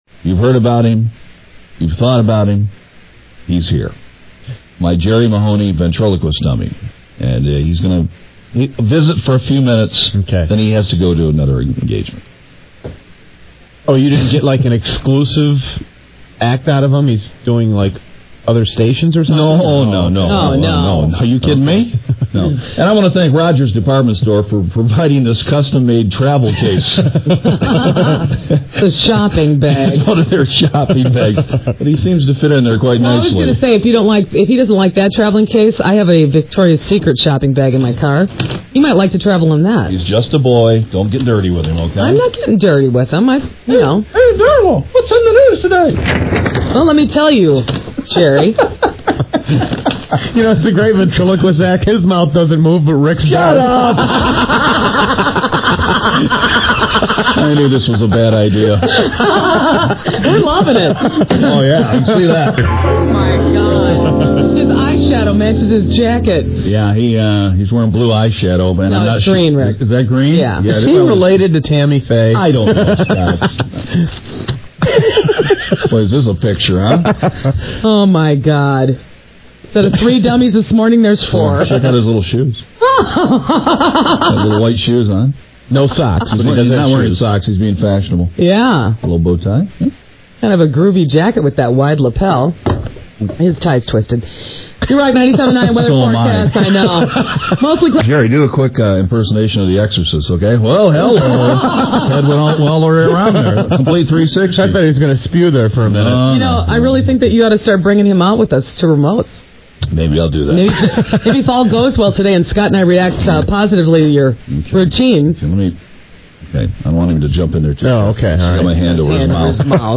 Ventriloquism on the Radio